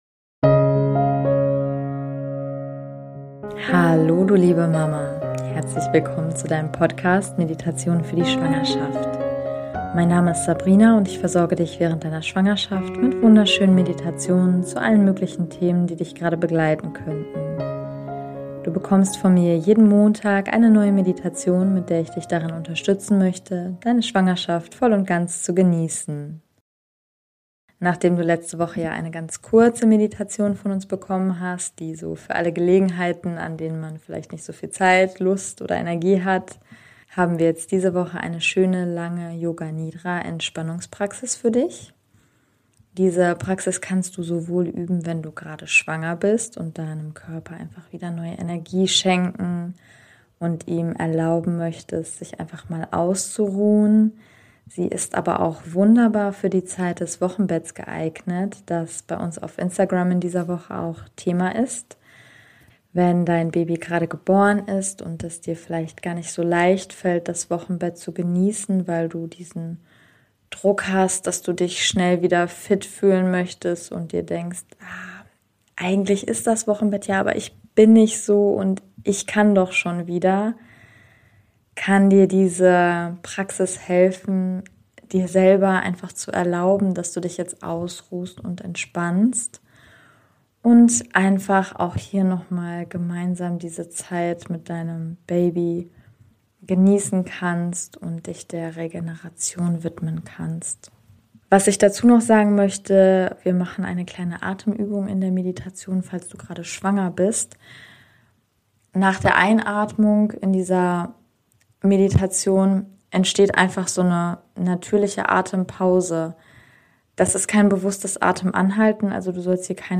Diese Yoga Nidra Praxis unterstützt dich während deiner Schwangerschaft und insbesondere auch im Wochenbett, einfach loszulassen und freizumachen von dem Druck, dass du gerade fit auf den Beinen herumspringen musst. Meditation beginnt ab Minute 04:12 ;-)